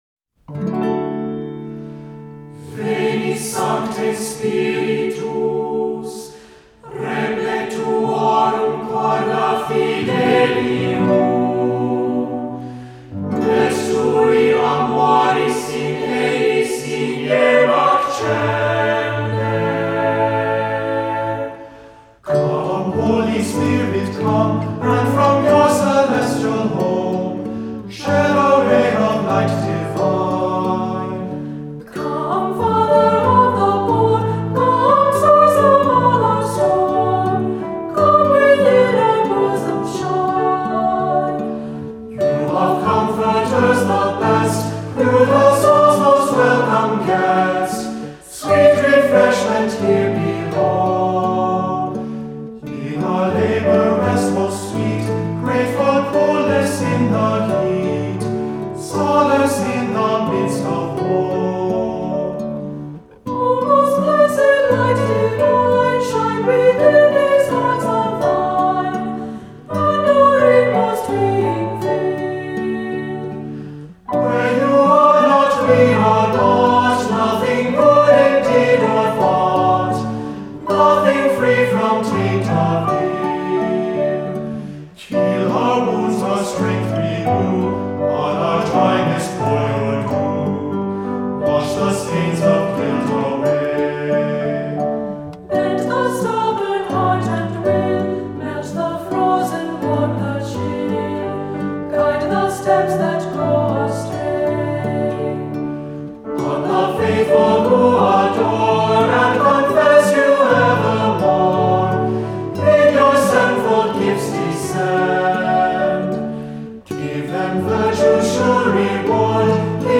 Voicing: Cantor,SATB